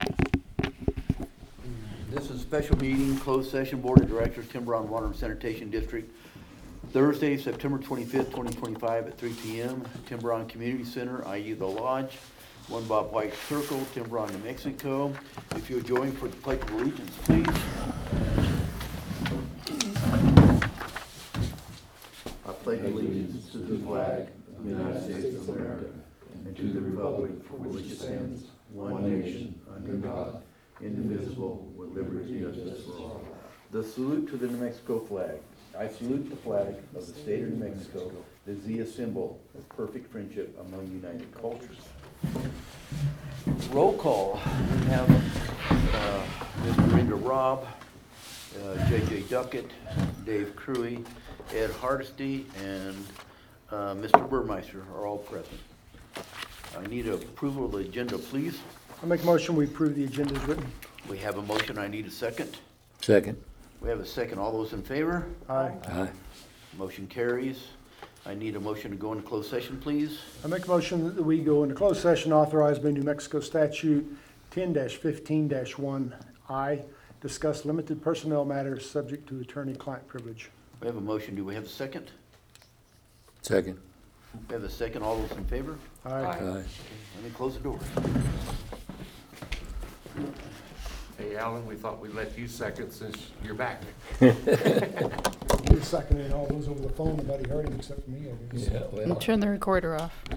Board Special Meeting
Please note - the location for this meeting is the Fire Station (not the Lodge).